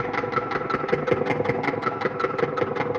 Index of /musicradar/rhythmic-inspiration-samples/80bpm
RI_DelayStack_80-02.wav